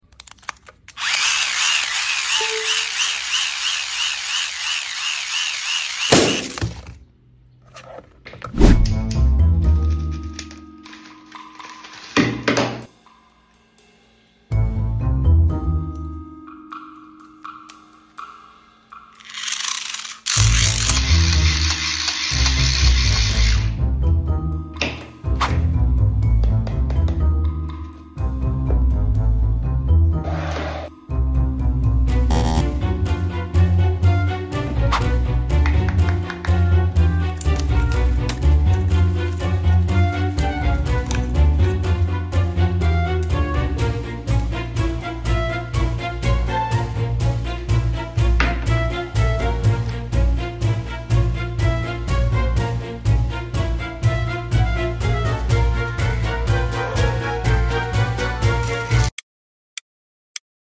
Hand Crank Charger 🪫🔋 Sound Effects Free Download